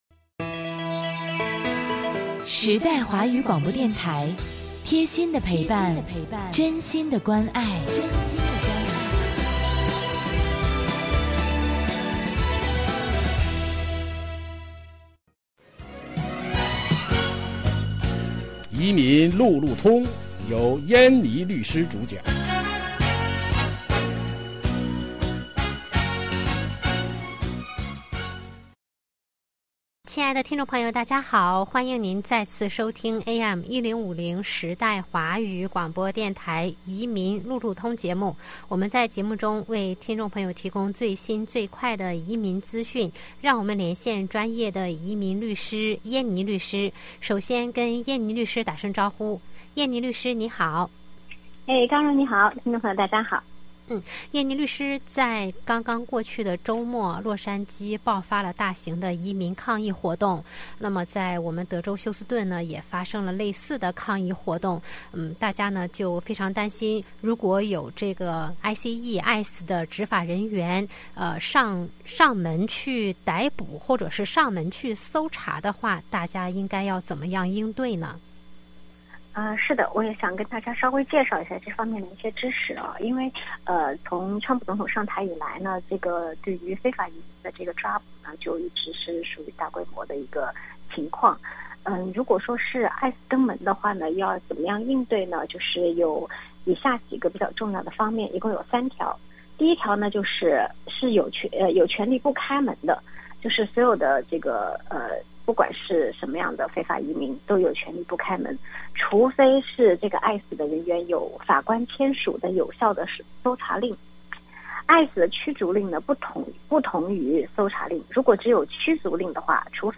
每月第二、第四个周一下午5点30分，AM1050时代华语广播电台现场直播，欢迎听众互动。